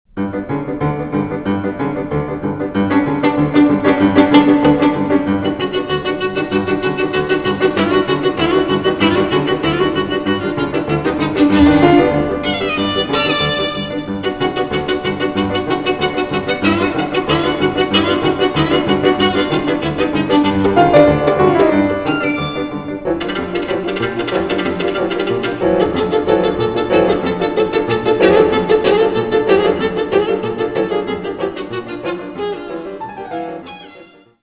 piano)hu
hegedűre és zongorára